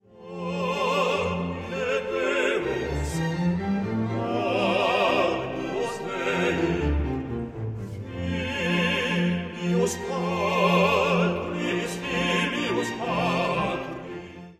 “Domine Deus” is a song for tenor and bass.
voices combining in a lovely, tender ballad.